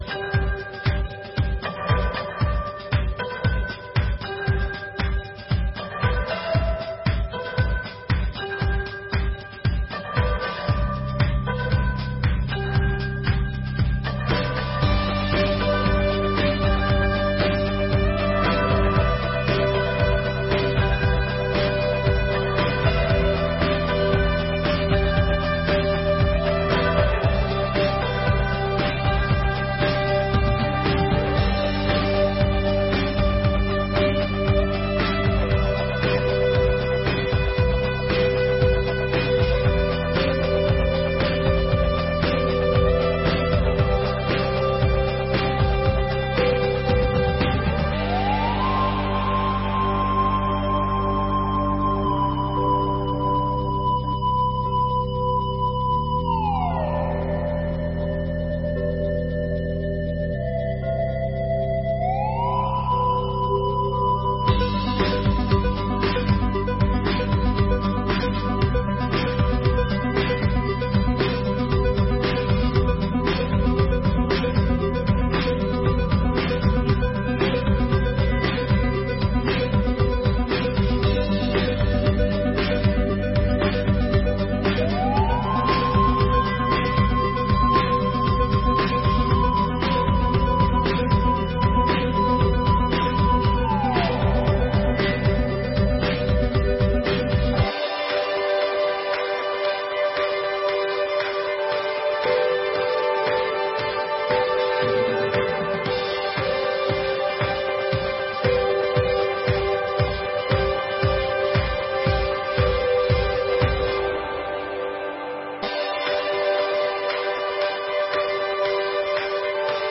Sessões Solenes de 2023